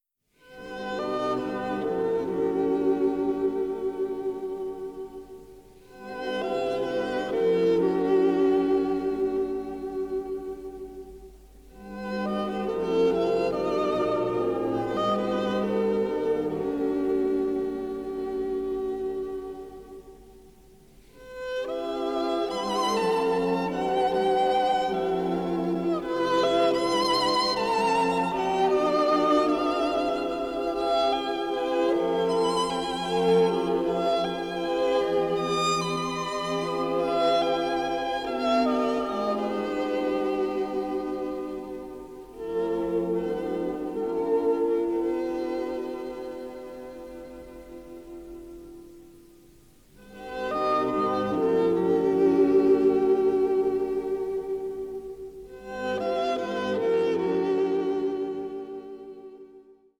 including the tango and Charleston